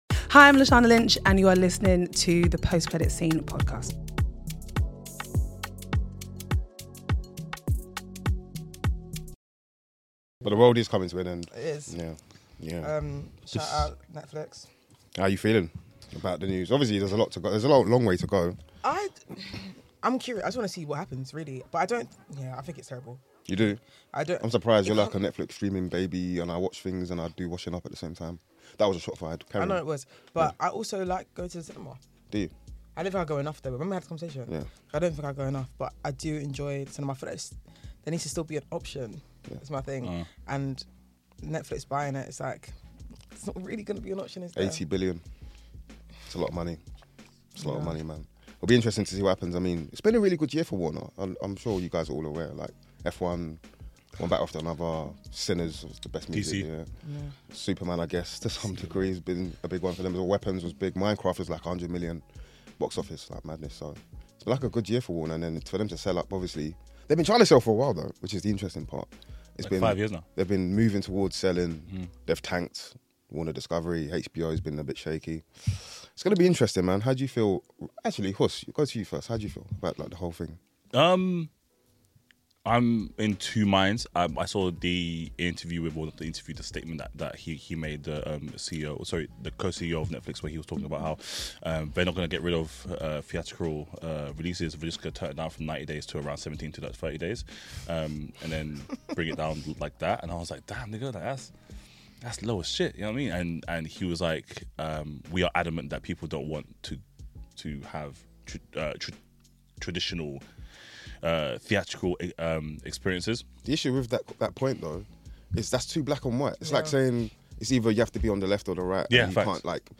public lecture